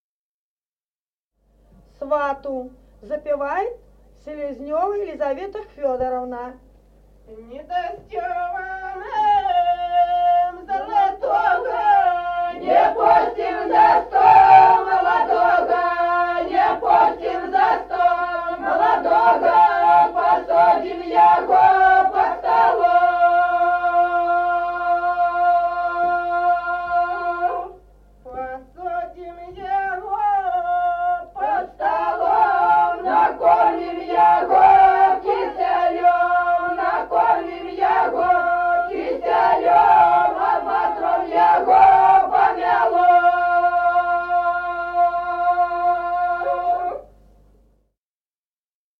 Народные песни Стародубского района «Не дасьтё вы нам», свадебная.